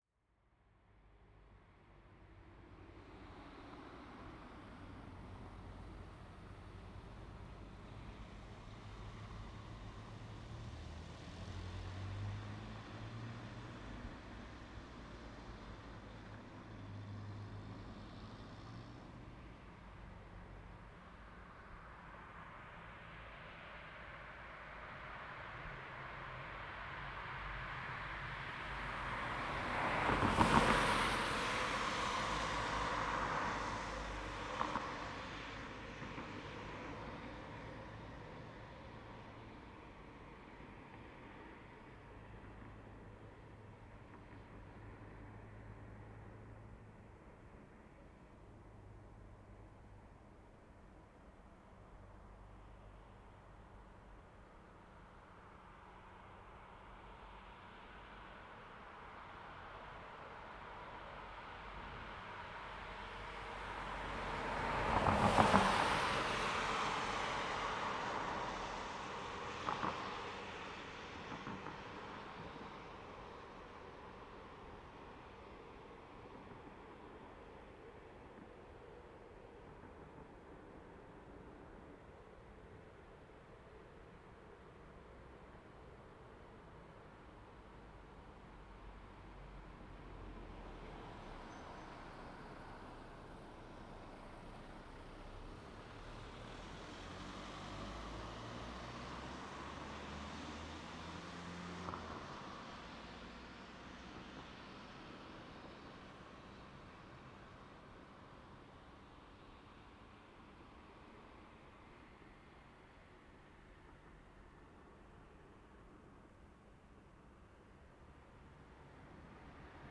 Night Traffic
Ambience Asphalt Bus Car Cars City Concrete Neighborhood sound effect free sound royalty free Sound Effects